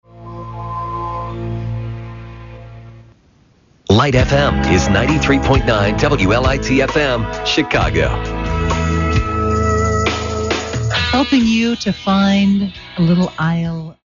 WLIT-FM Top of the Hour Audio: